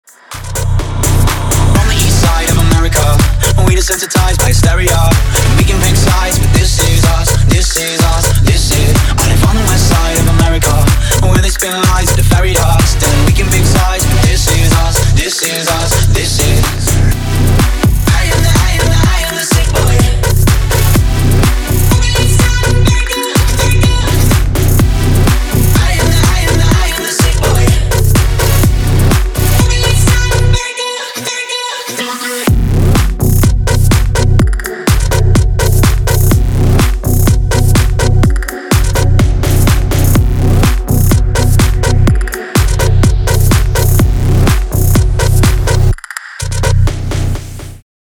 • Качество: 320, Stereo
мужской голос
громкие
dance
Electronic
электронная музыка
Bass
house